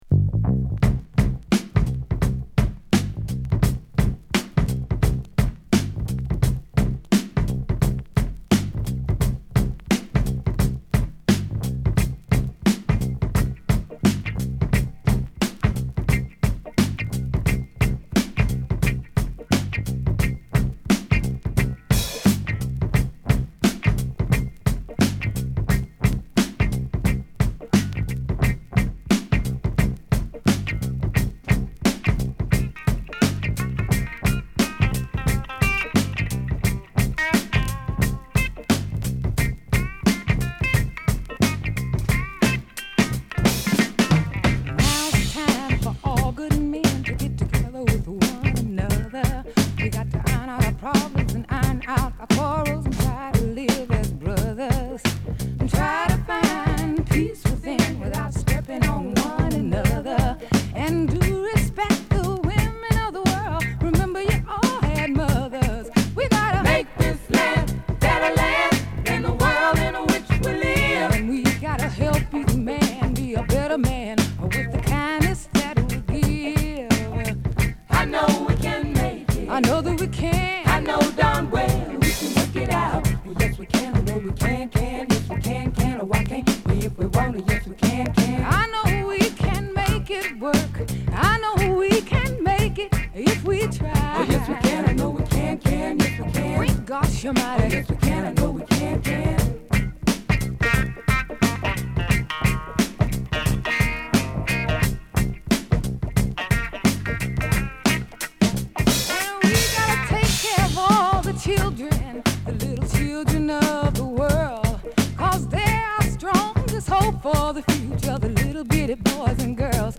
クラシックブレイク！
中盤にはシッカリドラムブレイクも有りで文句無しの１曲です。